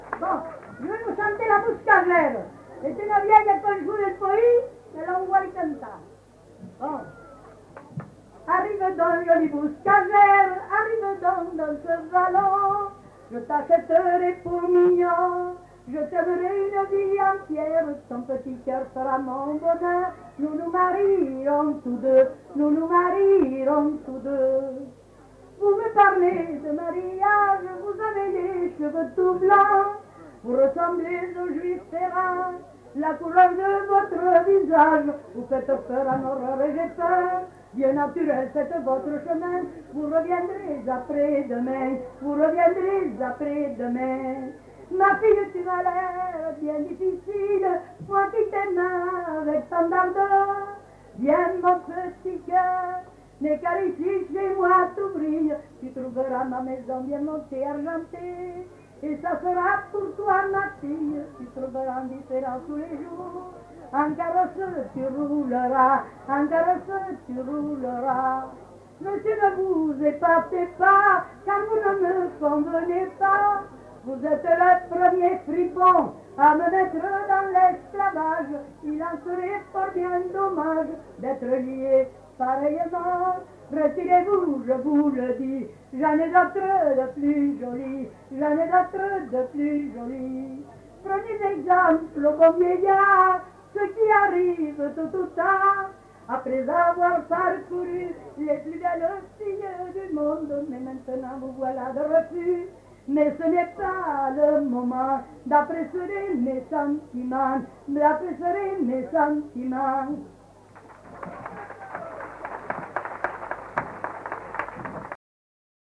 Lieu : Pons
Genre : chant
Effectif : 1
Type de voix : voix de femme
Production du son : chanté
• [enquêtes sonores] Musiques et chants enregistrés à Pons